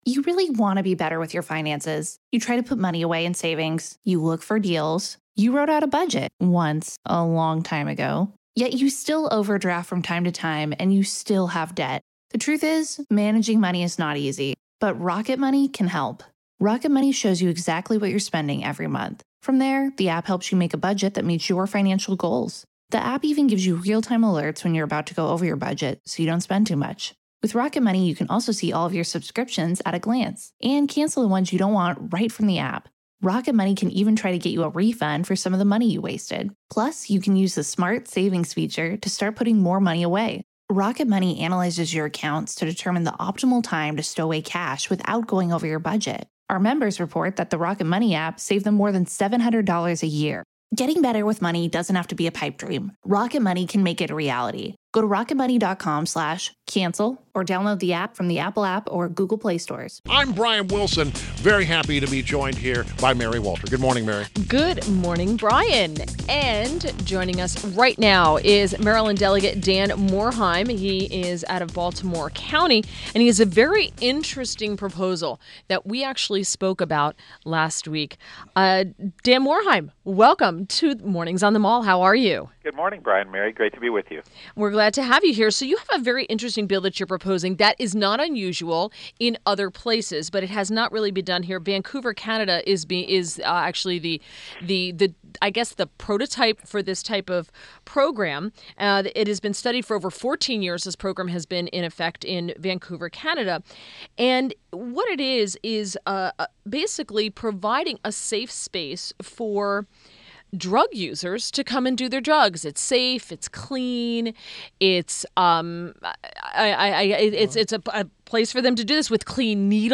WMAL Interview - DAN MORHAIM - 02-22-17
INTERVIEW – MARYLAND DELEGATE DAN MORHAIM – (MOR-HIGH-MM) — D-Baltimore County